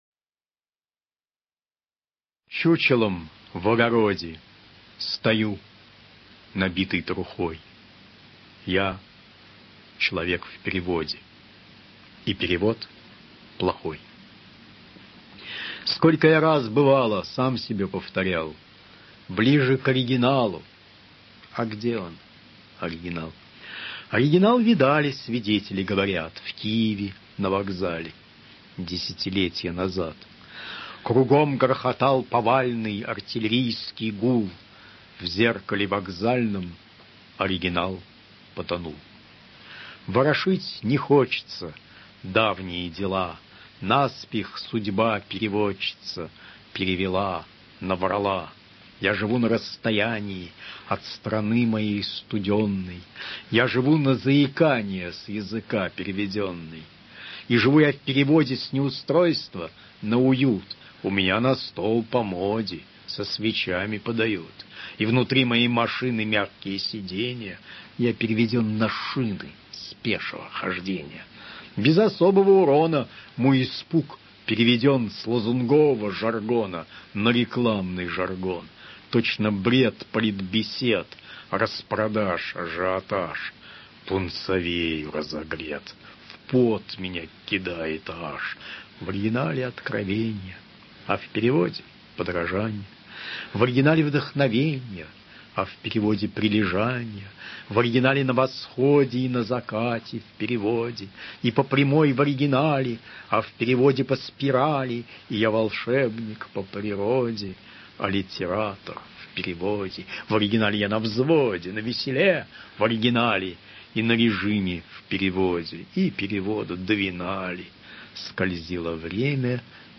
1. «Иван Елагин – “Чучелом в огороде…” (читает автор)» /
Elagin-Chuchelom-v-ogorode.-chitaet-avtor-stih-club-ru.mp3